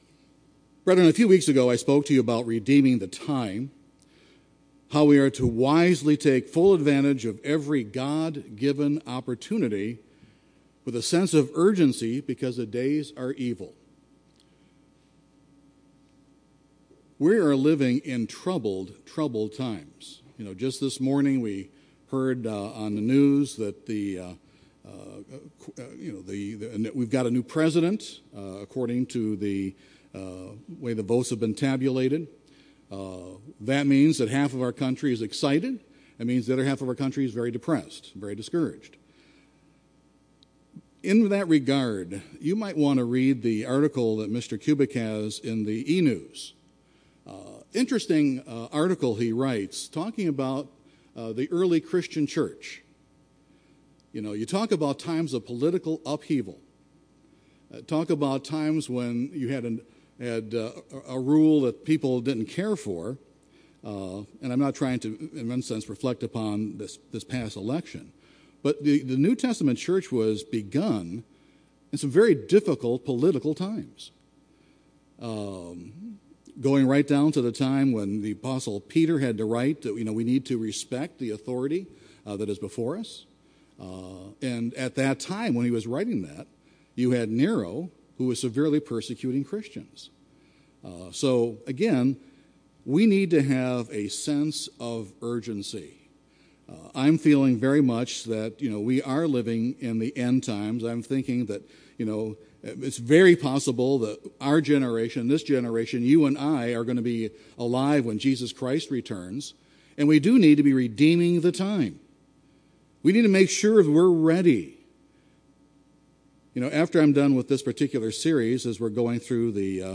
This sermon, part two of a series, gives food for thought on Jesus instructions on how to pray.